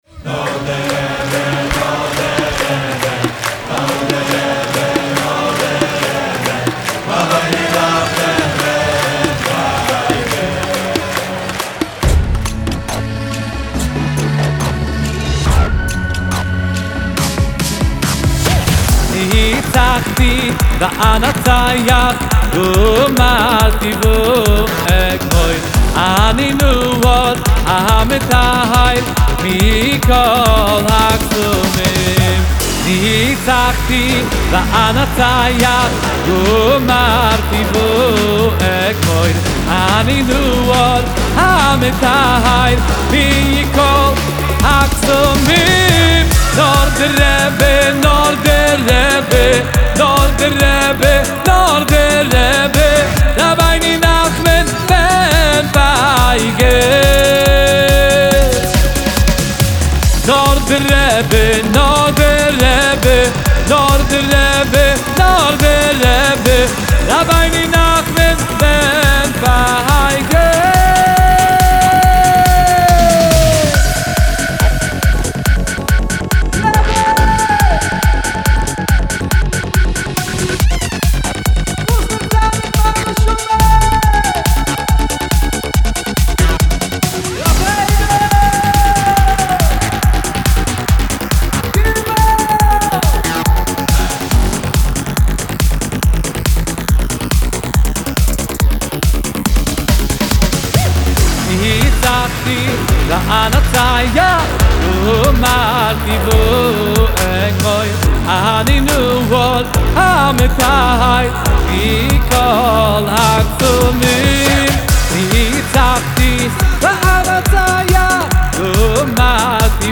הזמר האנרגטי
בסגנון אלקטרוני קצבי ומקפיץ